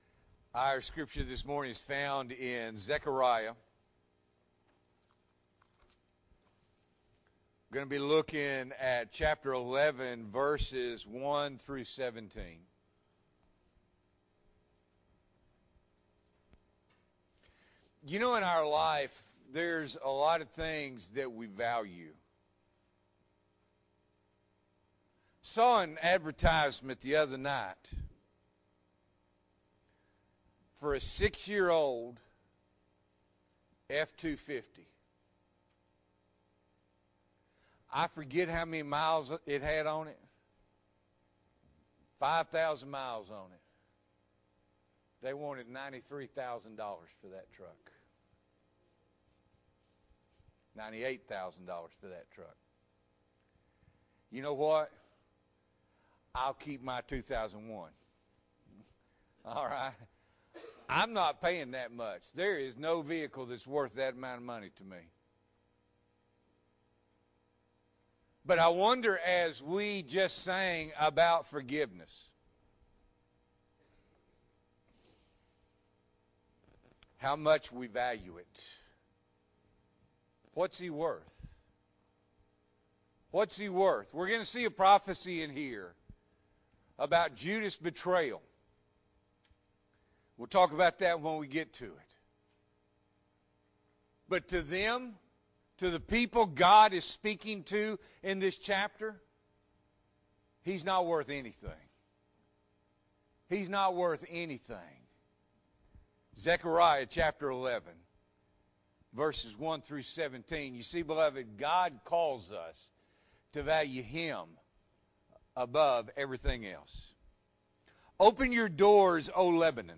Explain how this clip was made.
February 20, 2022 – Morning Worship